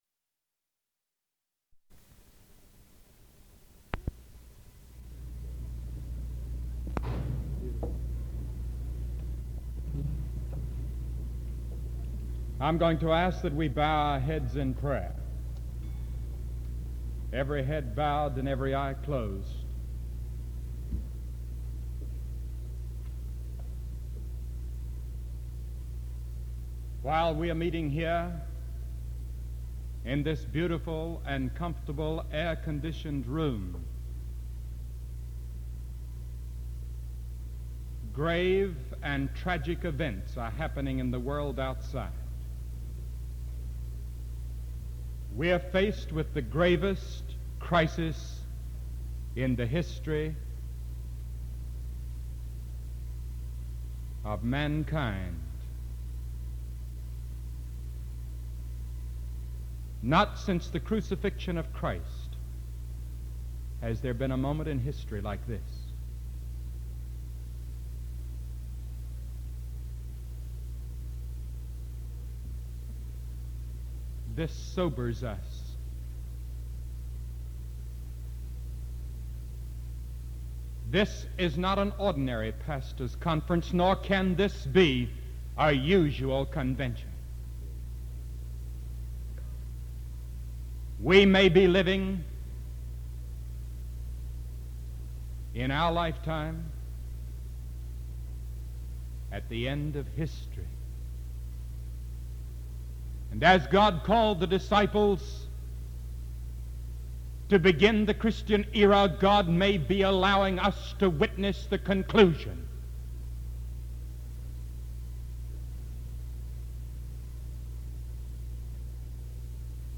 The 1960 Pastor’s Conference was held May 16-17, 1960, in Miami Beach, Florida.